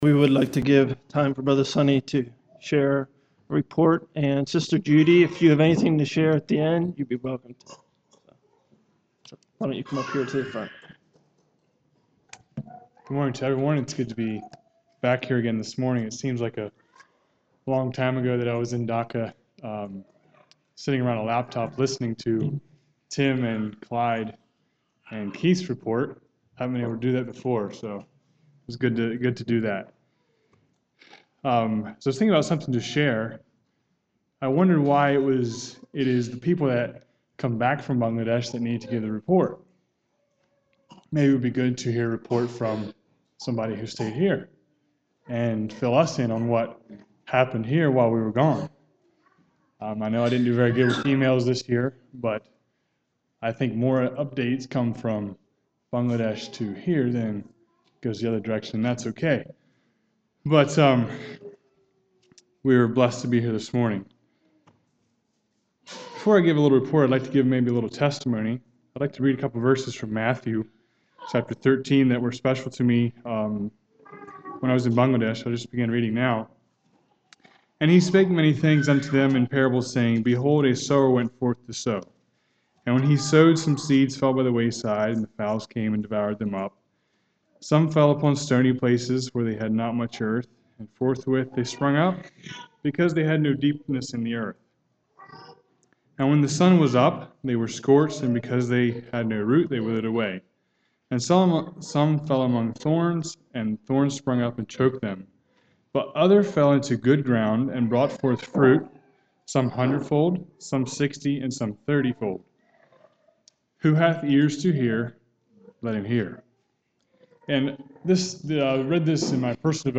Sermon
In today's culture the word Love means many different things to many different people, but Jesus' love or Agape Love for us is the ultimate standard for Love. This message also include a short testimony from a returning missionary at the out-start of the message.